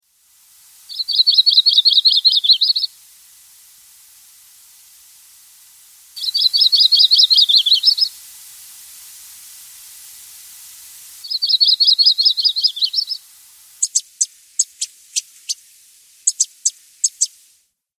Birdcalls
Yellow Rumped Warbler
warbler.mp3